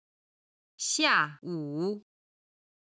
下午　(xià wǔ)　正午から日没まで
05-xia4wu3.mp3